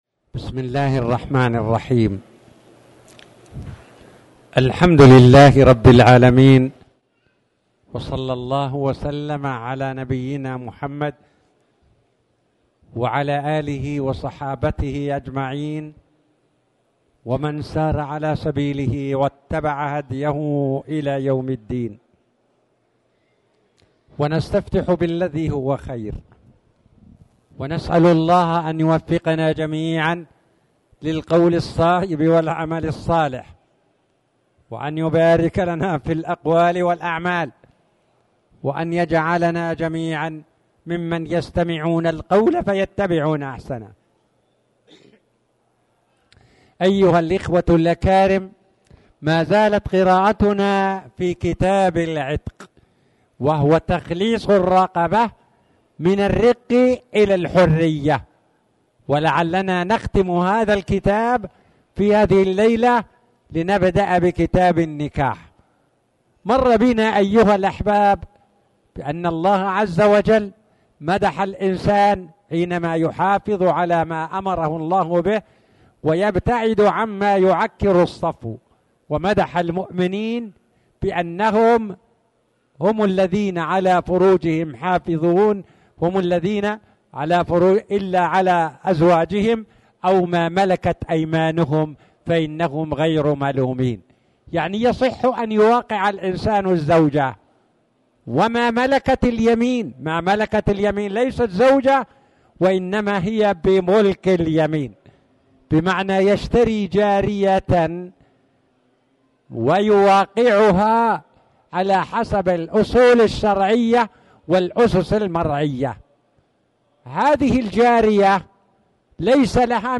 تاريخ النشر ٢ صفر ١٤٣٨ هـ المكان: المسجد الحرام الشيخ